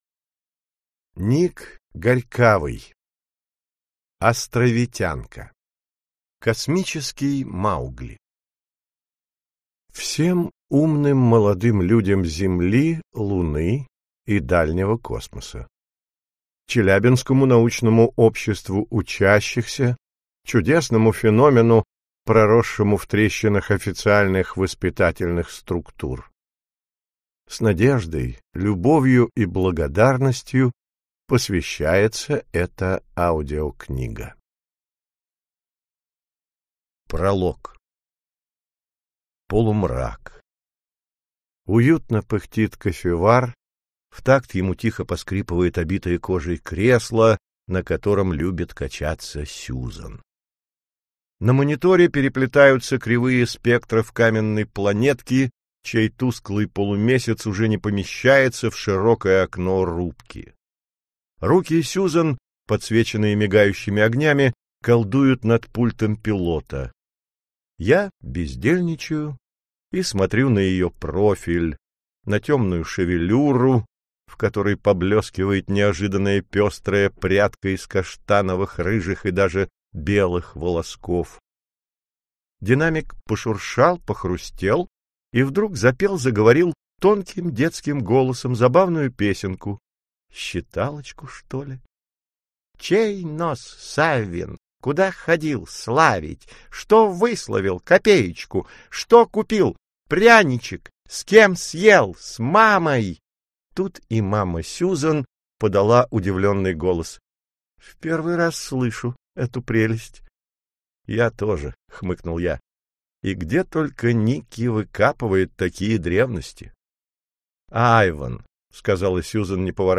Аудиокнига Астровитянка. Книга I. Космический маугли | Библиотека аудиокниг